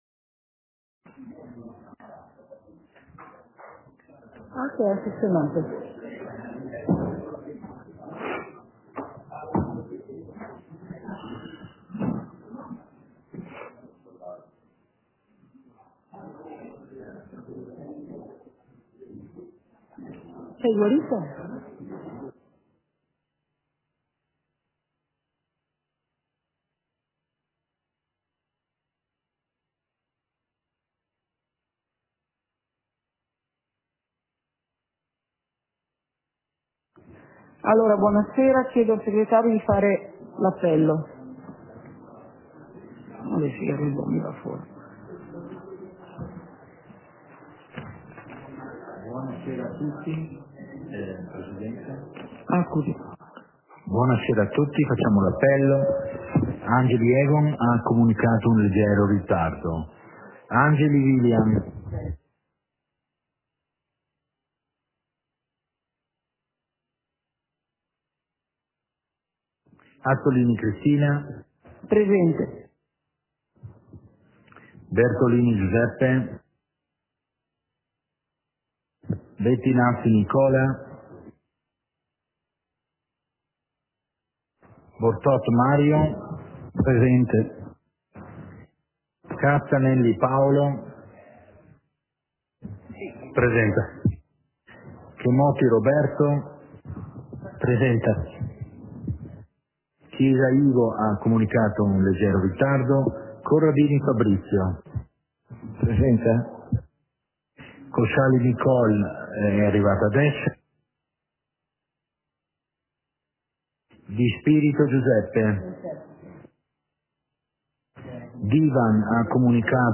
Seduta del consiglio comunale - 25.01.2023